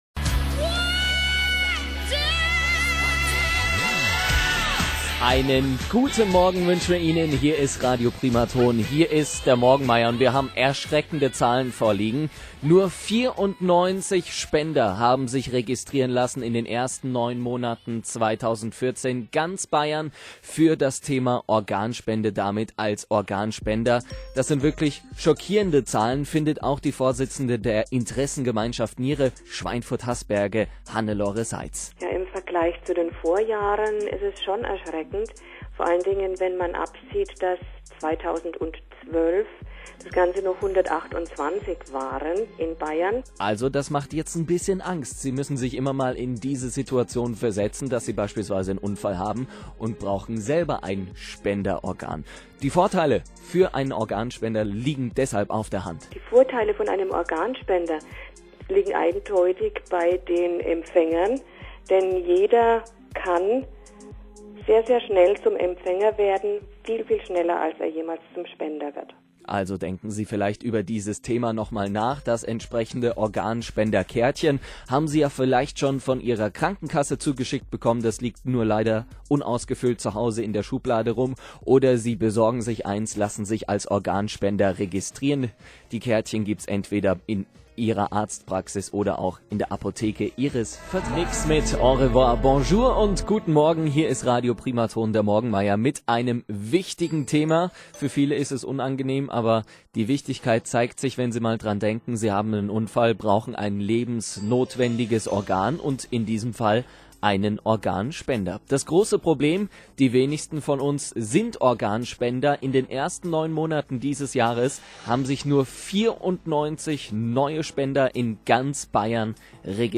Interview Radio Primaton
14_12_Primaton_Interview.wma